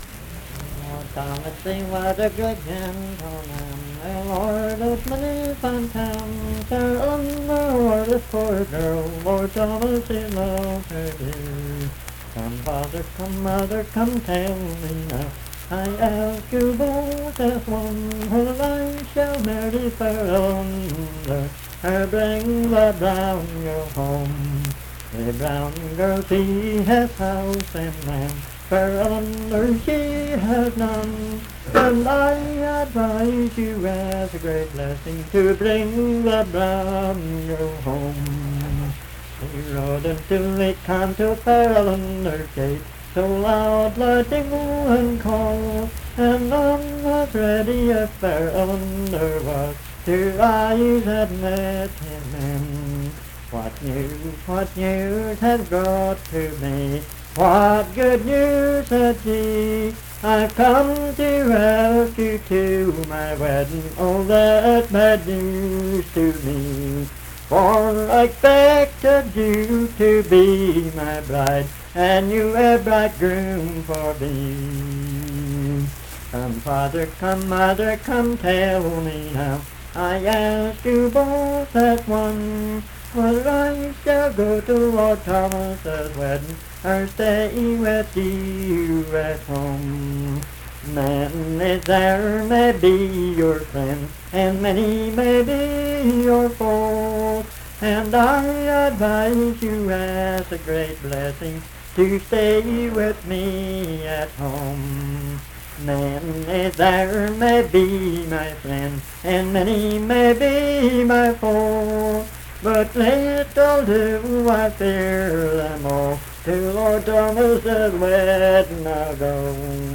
Unaccompanied vocal music
Performed in Dundon, Clay County, WV.
Voice (sung)